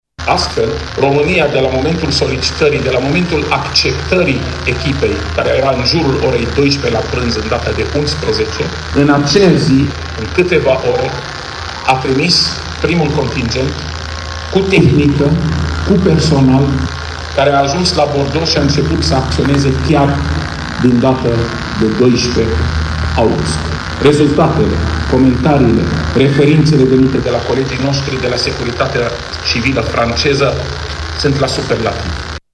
Raed Arafat a precizat că modul trimis de România în Franța a realizat o premieră: